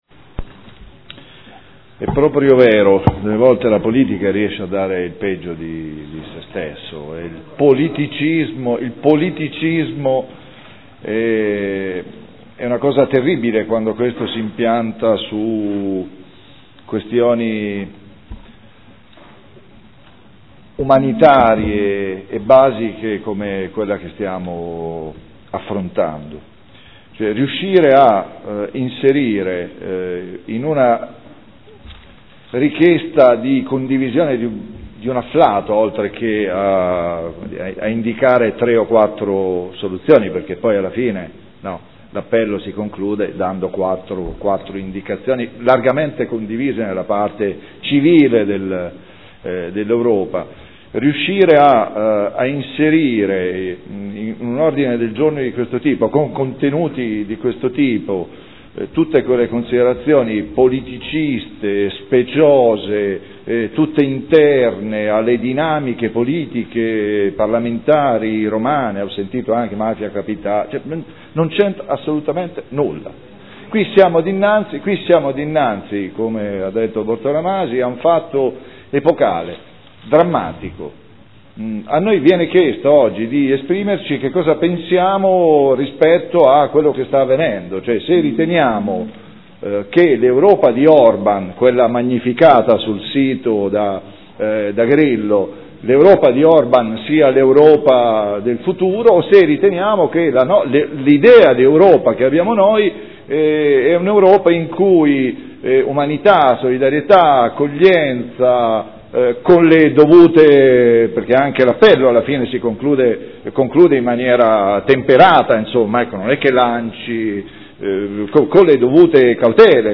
Paolo Trande — Sito Audio Consiglio Comunale
Presentazione odg n° 121020 " adesione all'appello: marcia delle donne e degli uomini scalzi". Dibattito